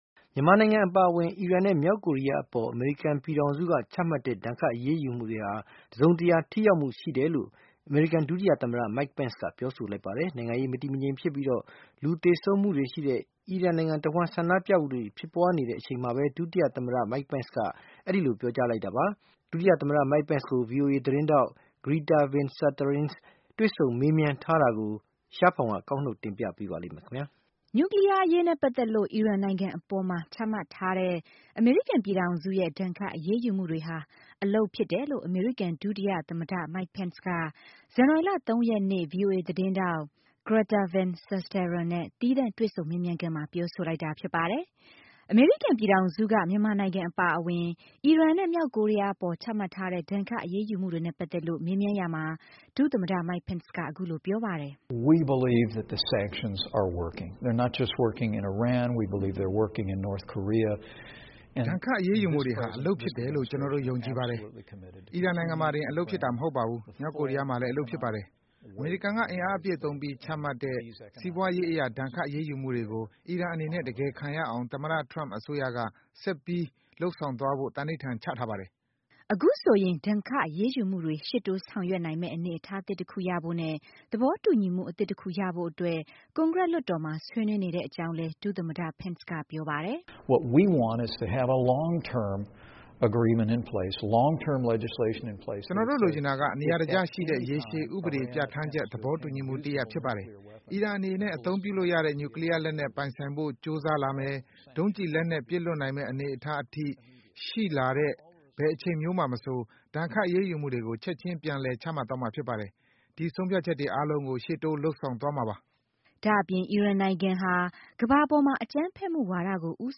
နျူကလီးယားအရေးနဲ့ပတ်သက်လို့ အီရန်နိုင်ငံအပေါ်မှာ ချမှတ်ထားတဲ့ အမေရိကန်ပြည်ထောင်စုရဲ့ ဒဏ်ခတ် အရေးယူမှုတွေဟာ အလုပ်ဖြစ်တယ်လို့ အမေရိကန် ဒုတိယ သမ္မတ Mike Pence က ဇန်နဝါရီလ ၃ ရက်နေ့ ဗွီအိုအေ သတင်းထောက် Greta Van Susteren နဲ့ သီးသန့်တွေ့ဆုံ မေးမြန်းခန်းမှာ ပြောဆိုလိုက်တာဖြစ်ပါတယ်။